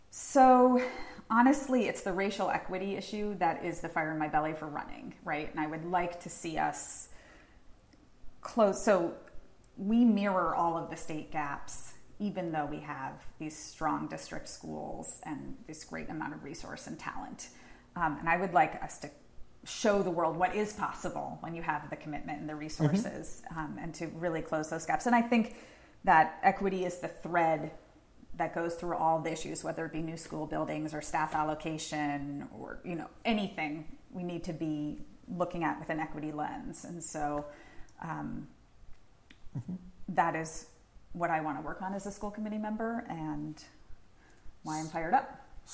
Q&A